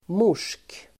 Ladda ner uttalet
Uttal: [mor_s:k]